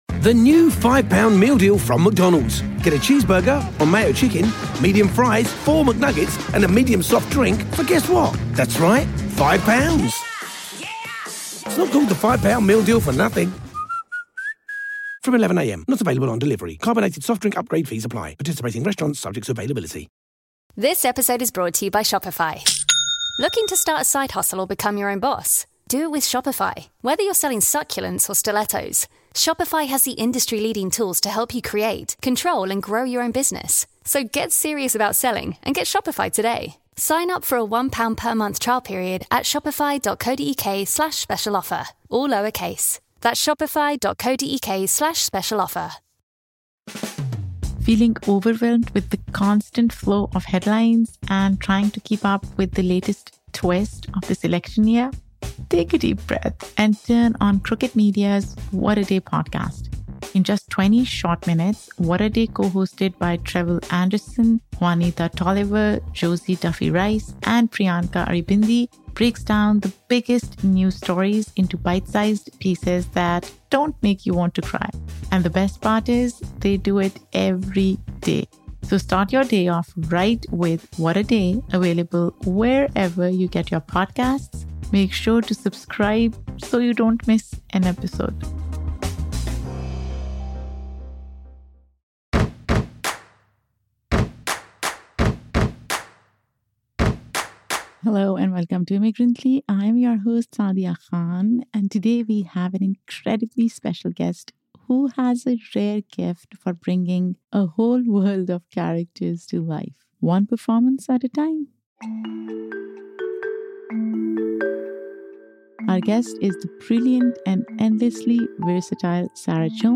In today’s episode, I’m joined by the phenomenal Sarah Jones, a Tony Award-winning actress and master of transformation.
We’ll explore her creative process, how she channels these voices, and what it all means for understanding identity and belonging in America. This conversation is equal parts entertaining and thought-provoking!